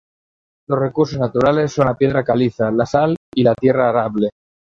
Pronúnciase como (IPA) /aˈɾable/